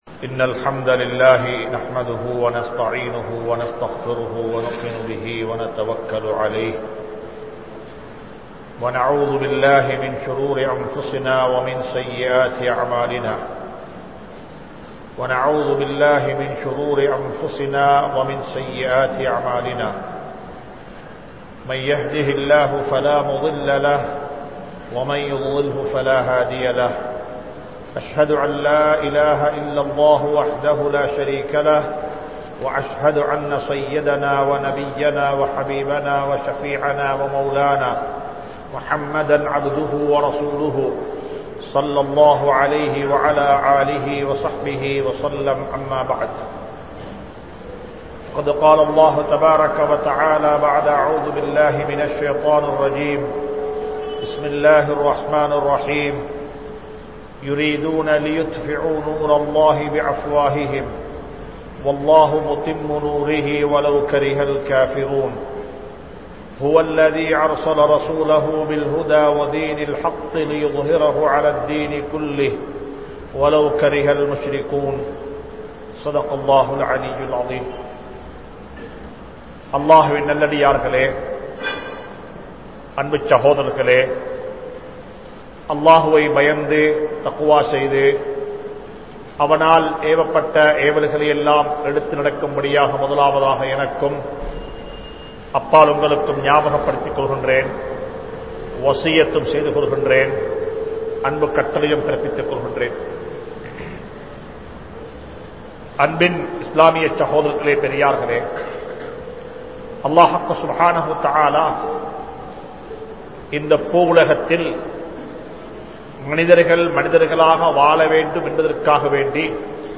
Muthalil Muslimkalaaha Vaalungal (முதலில் முஸ்லிம்களாக வாழுங்கள்) | Audio Bayans | All Ceylon Muslim Youth Community | Addalaichenai
Jamiul Akbar Jumua Masjidh